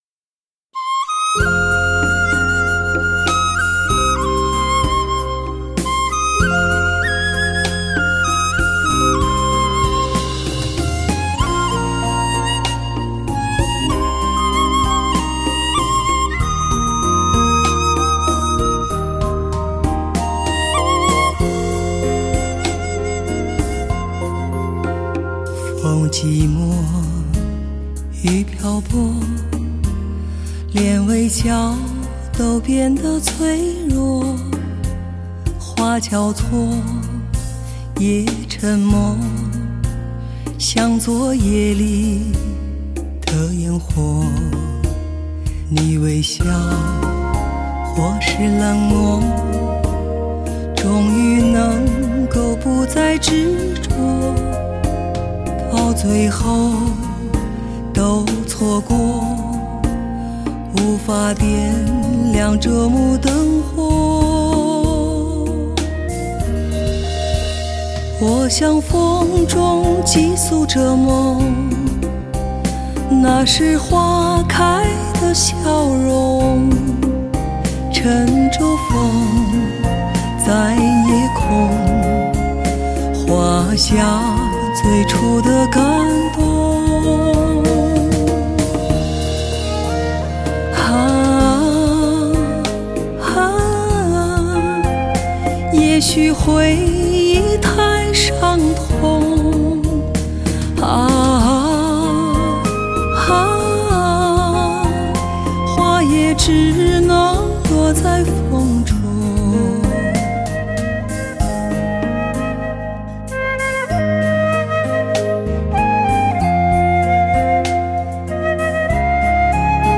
一张实力女声，声音绵厚充满磁性。
如绽放的花蕊，娇柔芬芳不做作。